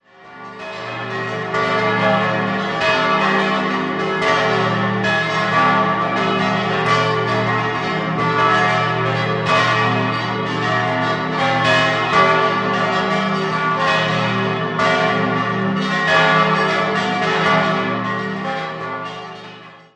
8-stimmiges Geläut: a°-cis'-e'-fis'-a'-h'-d''-fis'' Folgende Glocken stammen aus der Glockengießerdynastie Rosenlächer (Konstanz): Nr. 8 (1844), Nr. 7 (1780), Nr. 6 (1750), Nr. 4 (1817).